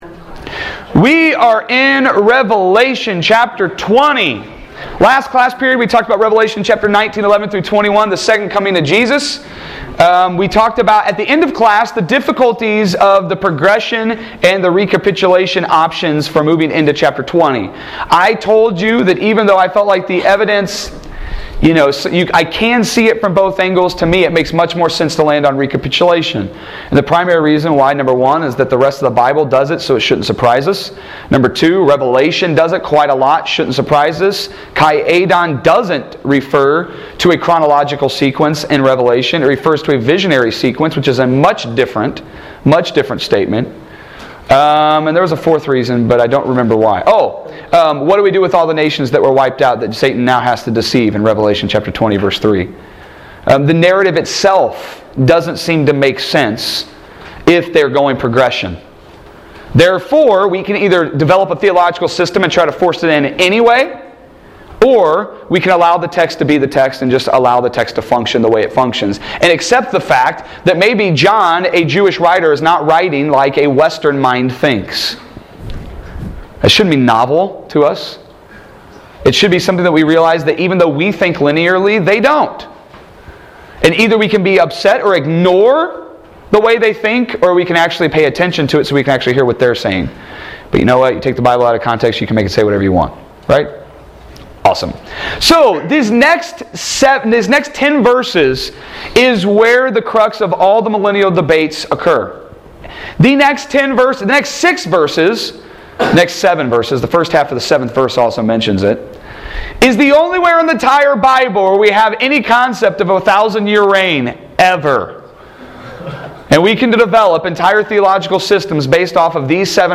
Audio Lectures